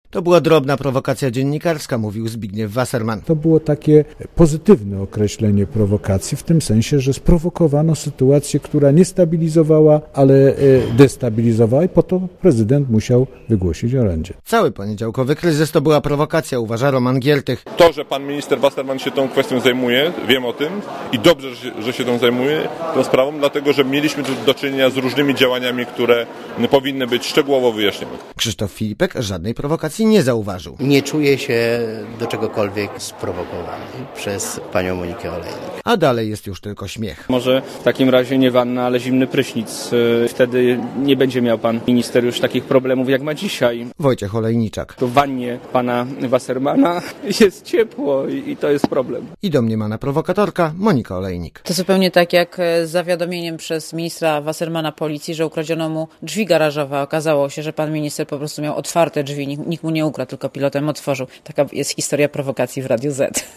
Źródło zdjęć: © PAP 15.02.2006 17:42 ZAPISZ UDOSTĘPNIJ SKOMENTUJ Relacja reportera Radia ZET